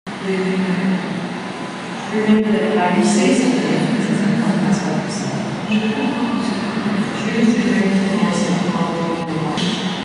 Mit unserer Intervention wollen wir die im Innern des KKLs entstehenden Geräusche, auf den öffentlichen Europaplatz bringen.
kunstmuseum.mp3